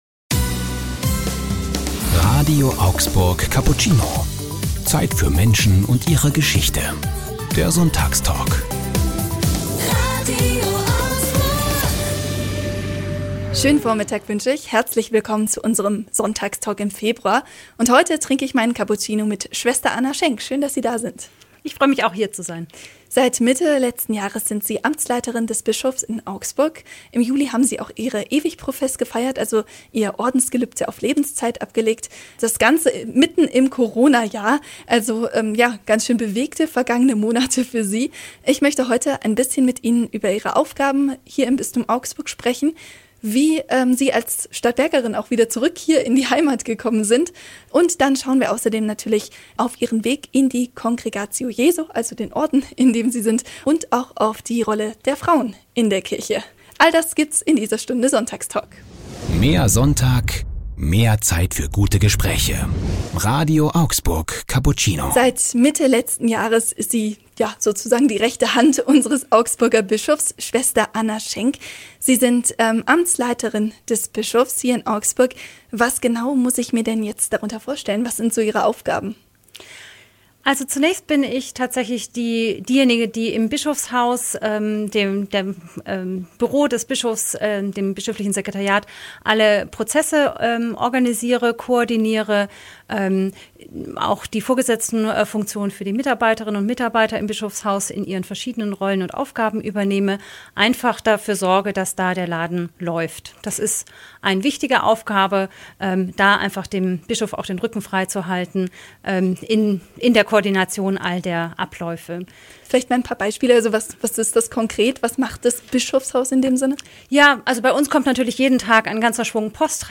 Sonntagstalk